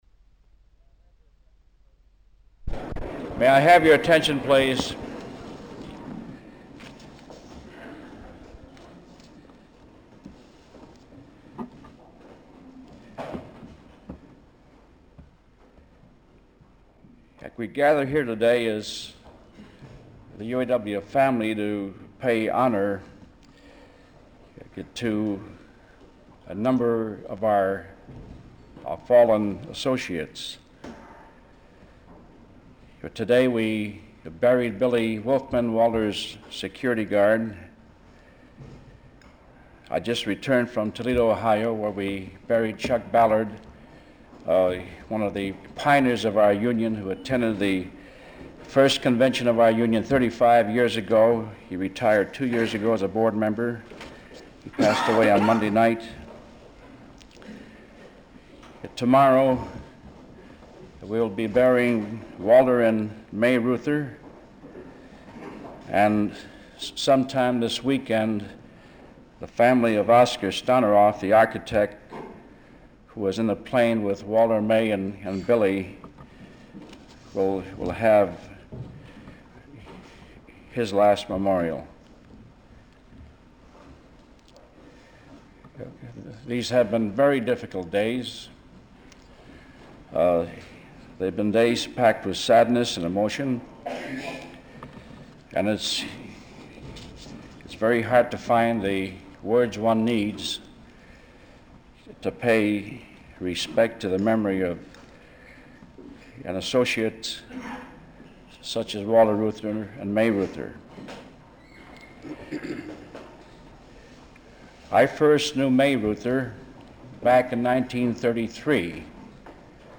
Walter P. Reuther Digital Archive · Walter P. and May Reuther - Memorial Service - Solidarity House, Reel 1, Detroit, MI · Omeka S Multi-Repository